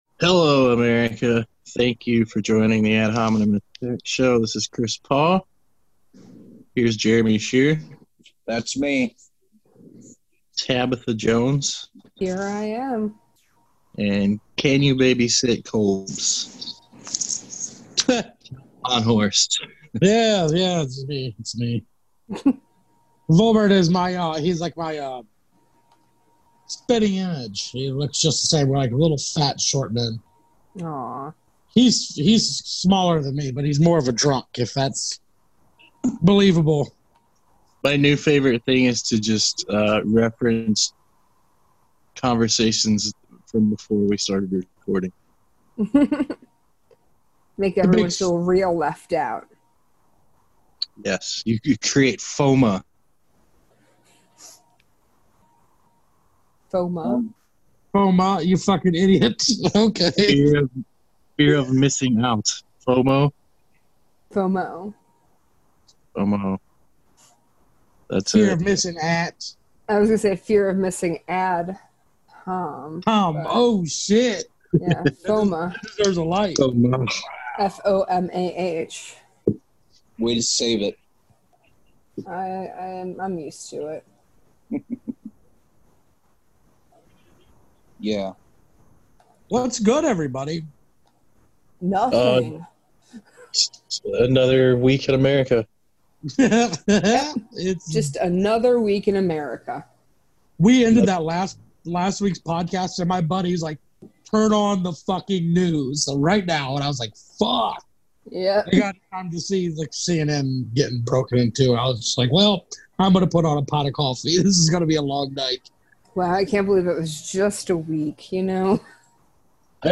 Recorded with Zoom.